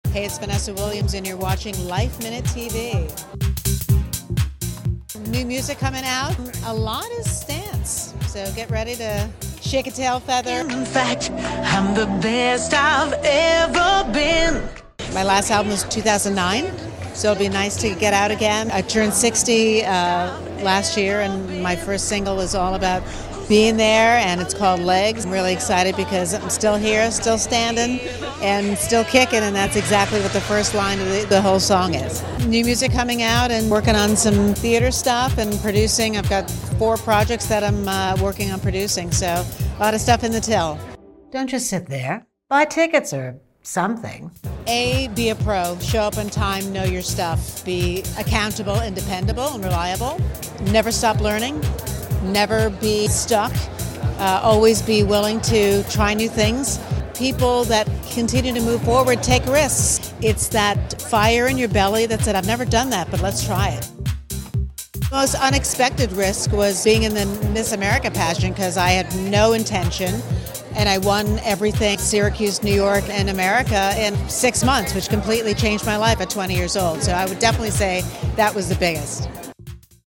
Triple threat, Vanessa Williams turned 60 last year, but she reassured us she is not slowing down anytime soon when we caught up with her at Pamella Rolands’ New York Fashion Week Fall 2024 runway show this month. The actress/singer is releasing new music (her first album since 2009), a dance record that she said will make you shake a tail feather.